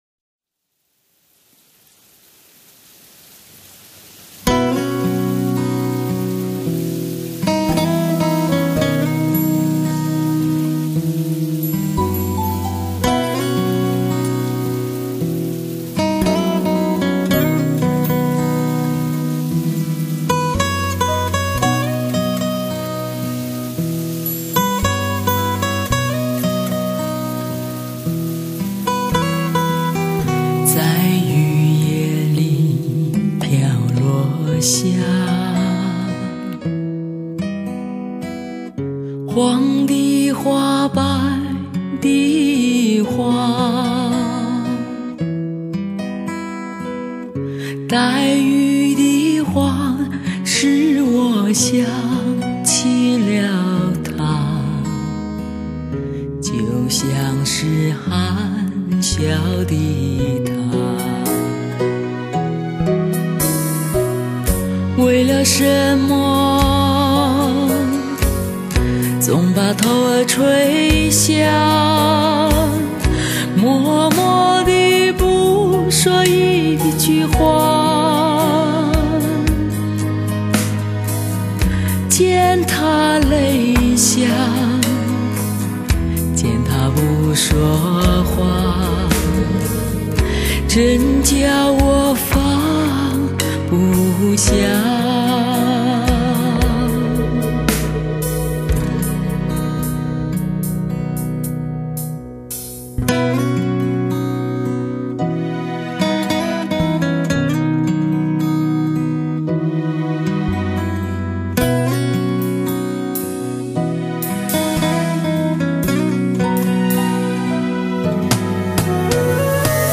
最 耐听的磁性嗓音，匹配最经典的歌曲，质感深沉，优雅婉转。